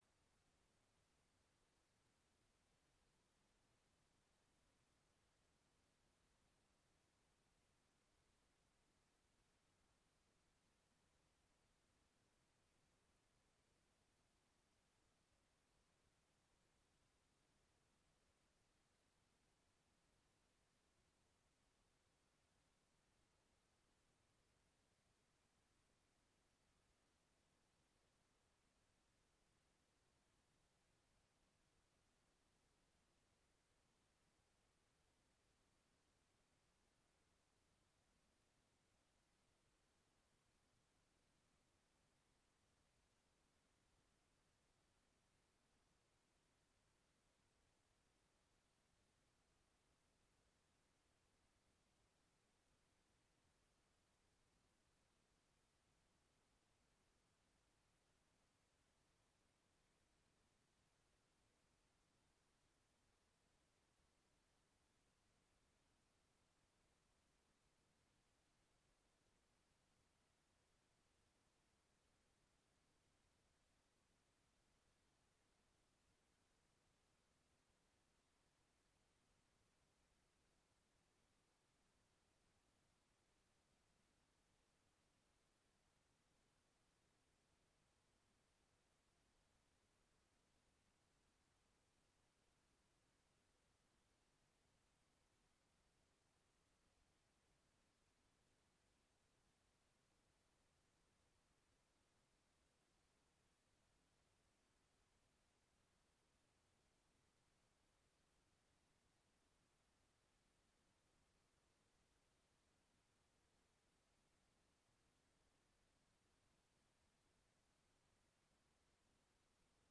Rom: K102